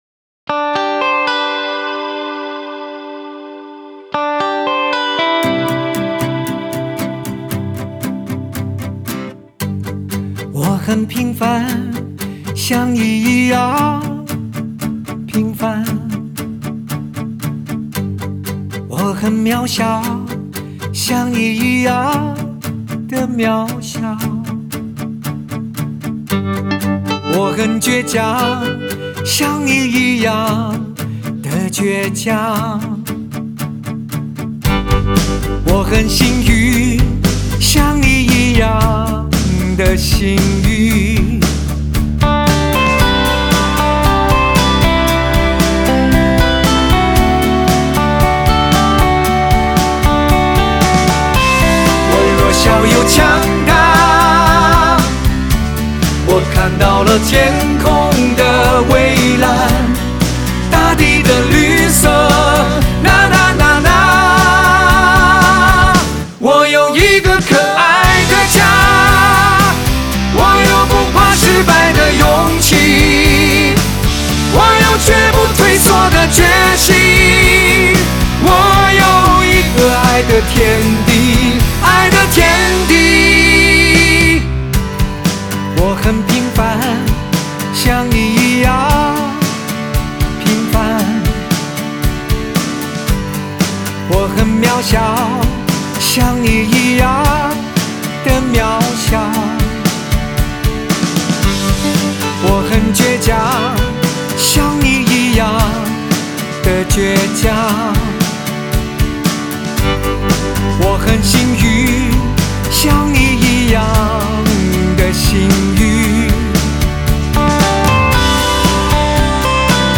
Ps：在线试听为压缩音质节选
吉他